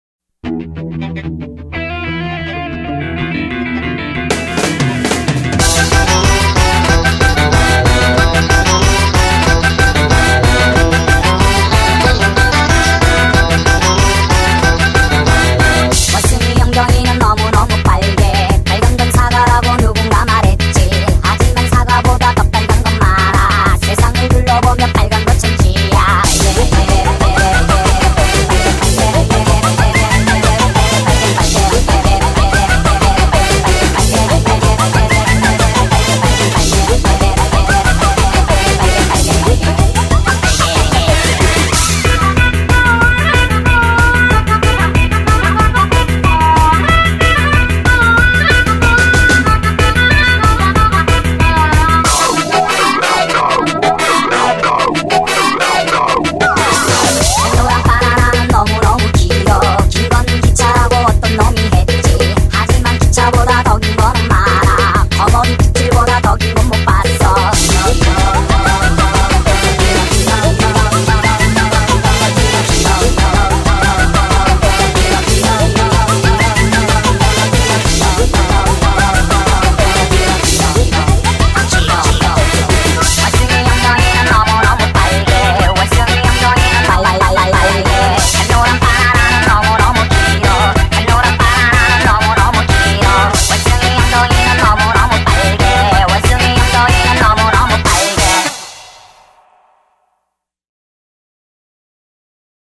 BPM186--1
Audio QualityPerfect (High Quality)